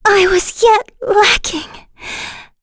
Laias-Vox_Dead.wav